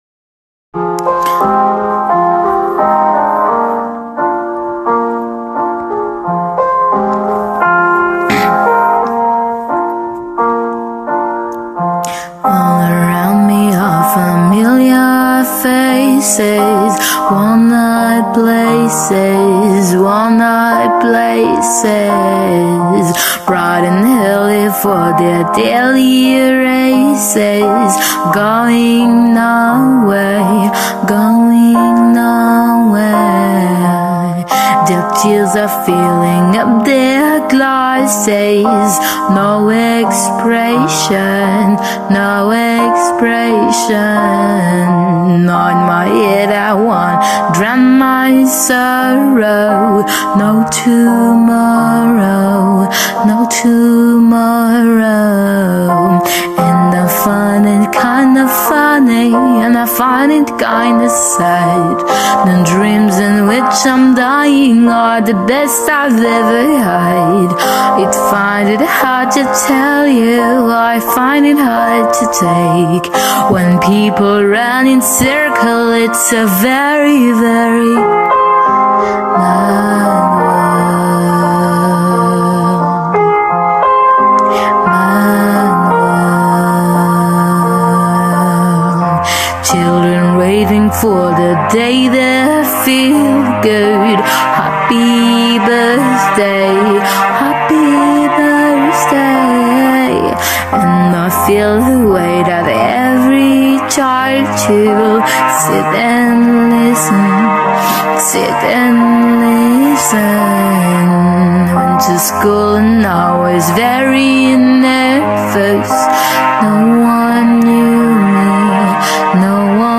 - Mezzo-soprano